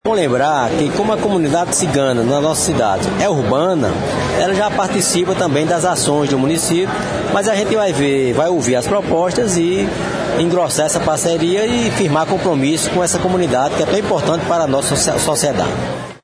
O prefeito em exercício, Bonifácio Rocha, acompanhado de secretários municipais, participou, na manhã desta quarta-feira (29/03), de uma audiência pública realizada pelo Ministério Público Federal no auditório do SEBRAE.
Fala do prefeito em exercício, Bonifácio Rocha –